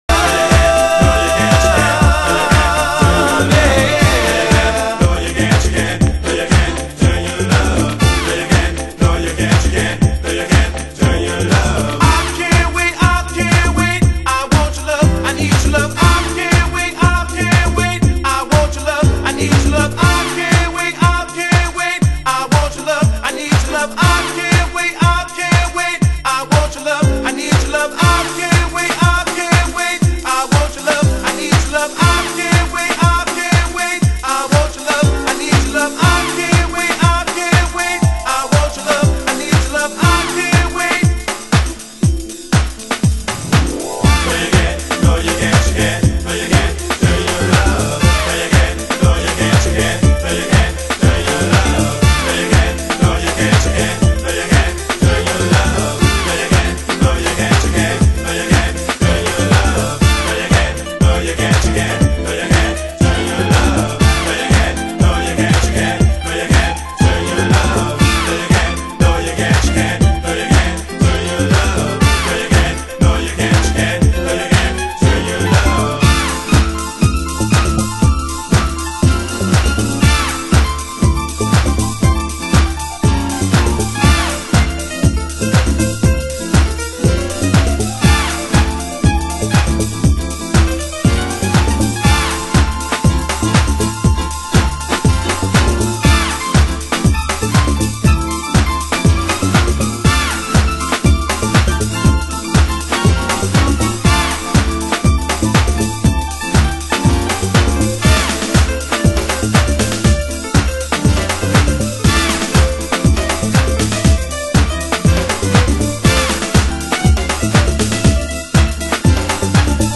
HOUSE MUSIC
盤質：軽いスレ、小傷、少しチリパチノイズ有